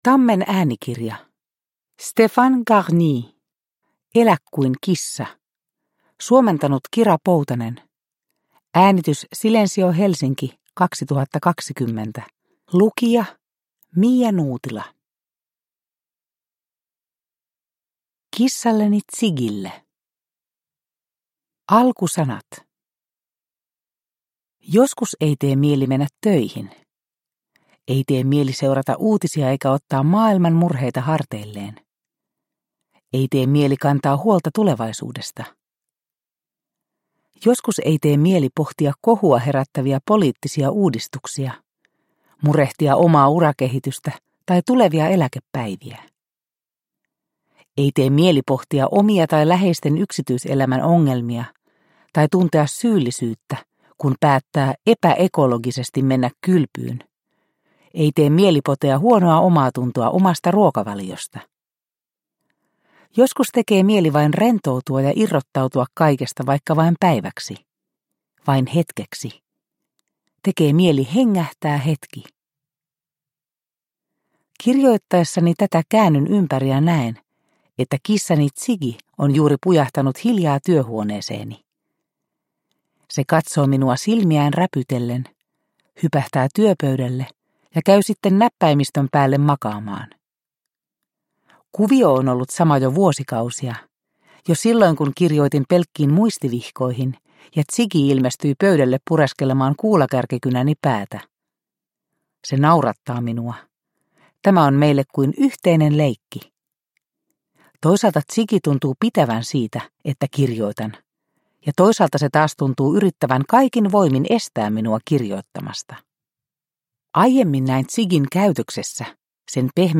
Elä kuin kissa – Ljudbok – Laddas ner